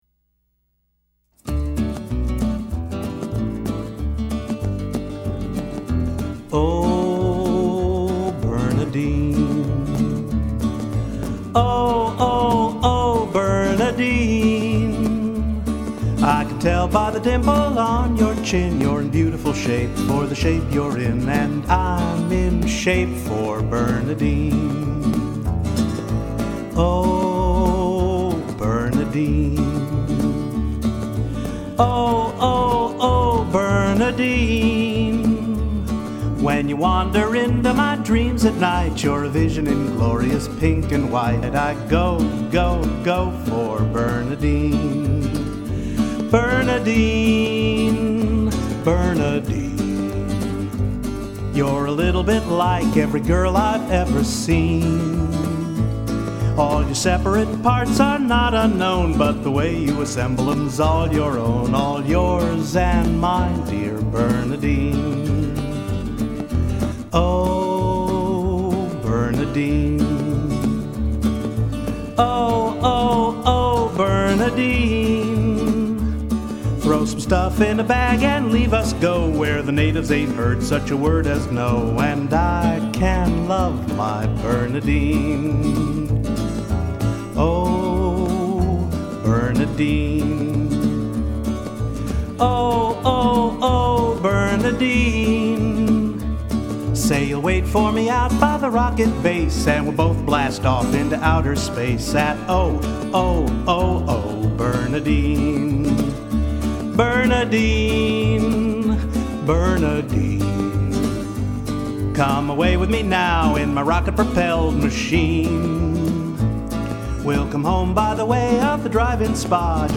Wasn’t that a cute little ditty?